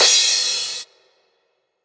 Crash MadFlavor 2.wav